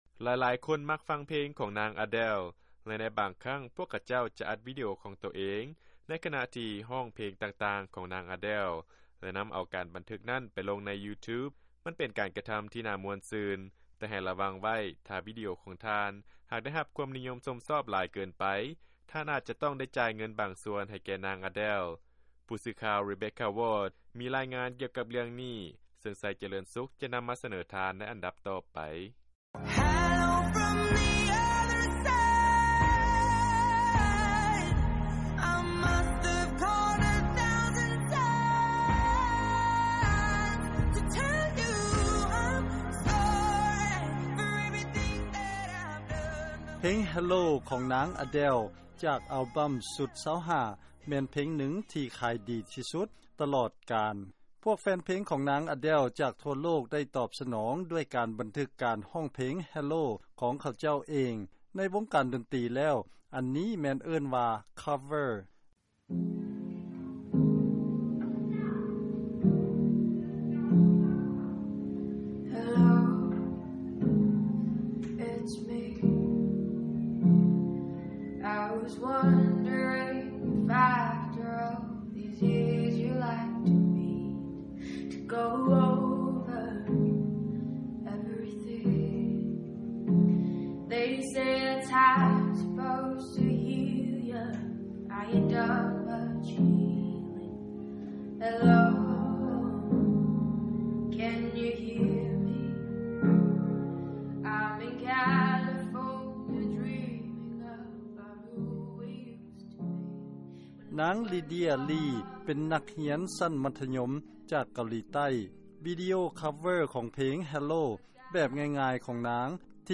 ເຊີນຟັງ ລາຍງານ ການຮ້ອງເພງ hello ຂອງນາງ Adele ລົງ YouTube ອາດຕ້ອງໄດ້ເສຍຄ່າ ໃຊ້ຈ່າຍ ໃຫ້ແກ່ນາງ ກໍເປັນໄດ້.